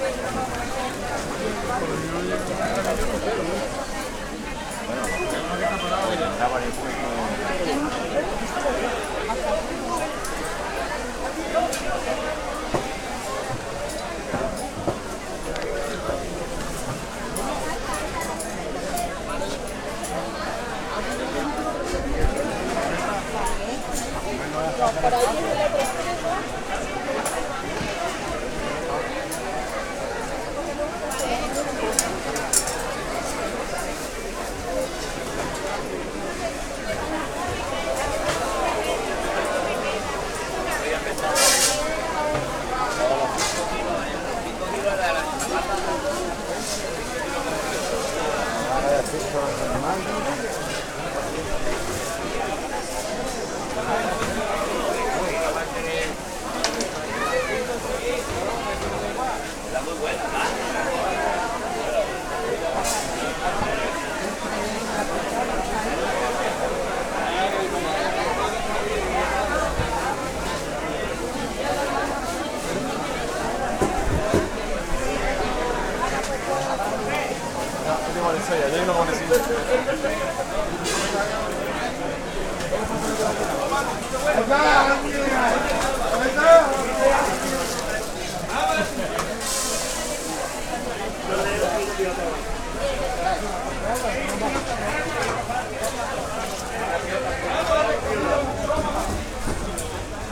market-2.ogg